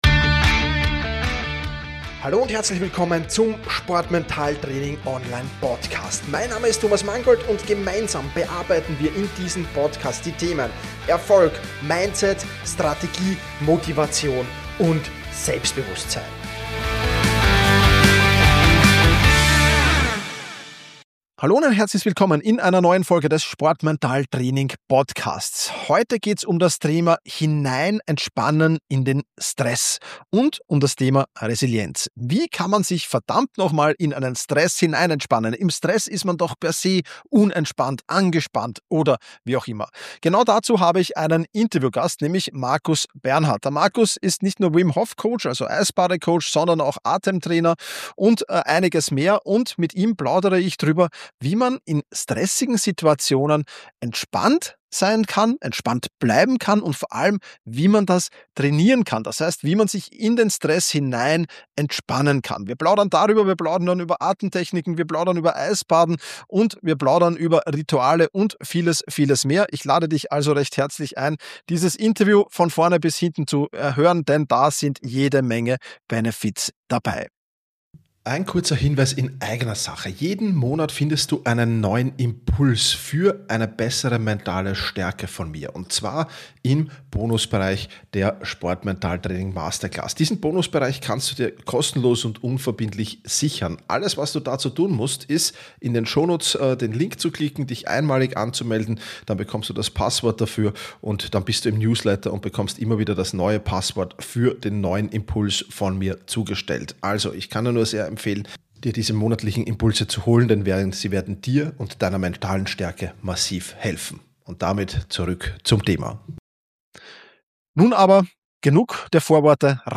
Im Interview erklärt er, wie das am besten funktioniert.